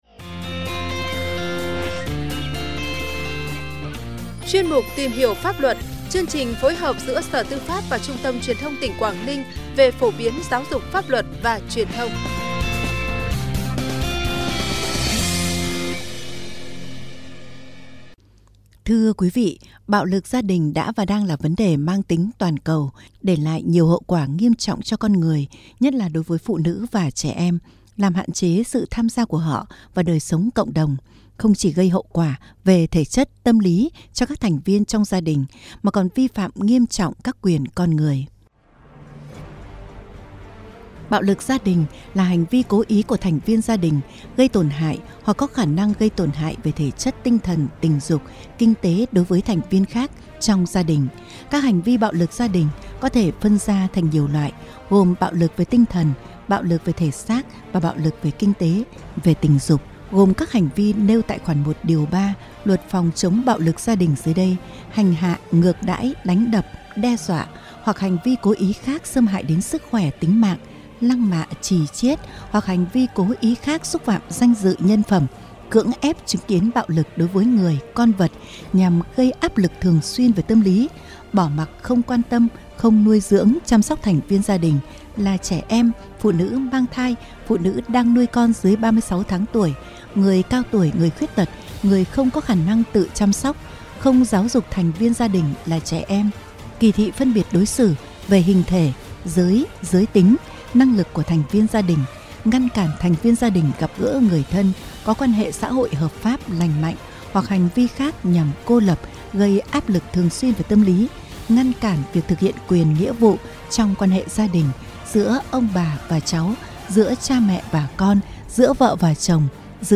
(Phóng Sự Phát Thanh) Tìm Hiểu Một Số Quy định Của Pháp Luật Về Phòng, Chống Bạo Lực Gia đình » Phổ Biến, Giáo Dục Pháp Luật Tỉnh Quảng Ninh